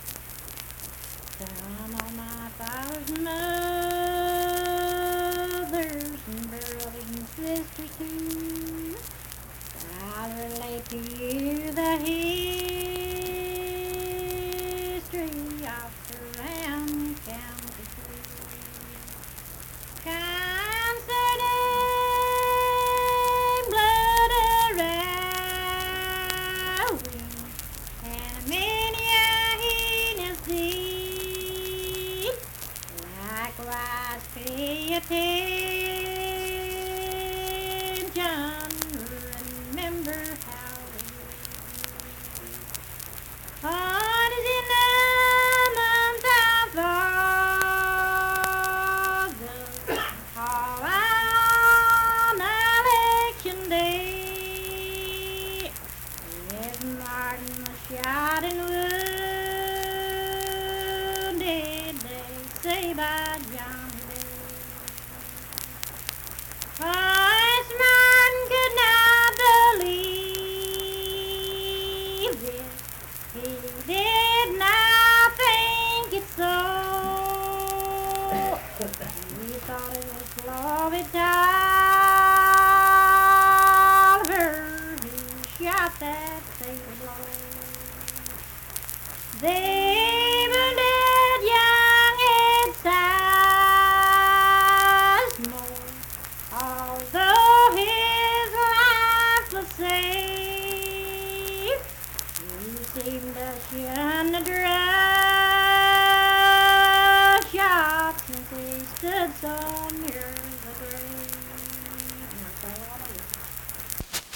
Unaccompanied vocal music
Verse-refrain, 5(4).
Voice (sung)